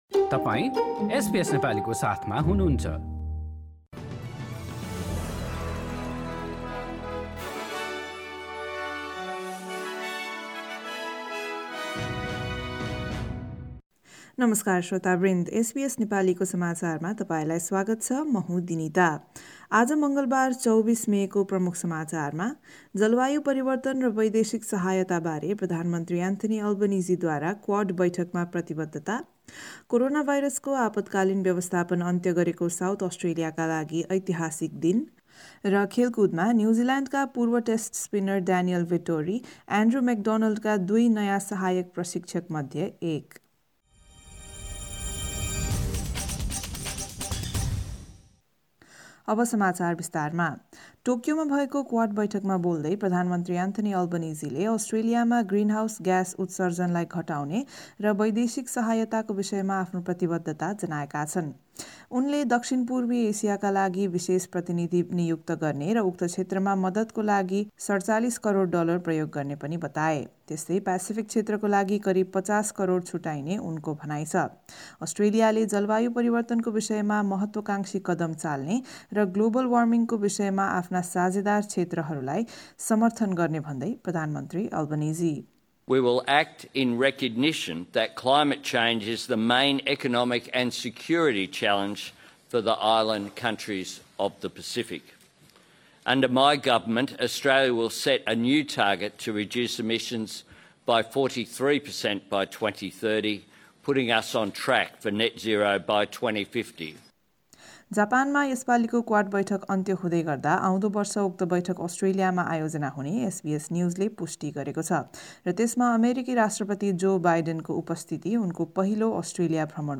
एसबीएस नेपाली अस्ट्रेलिया समाचार: मङ्गलबार २४ मे २०२२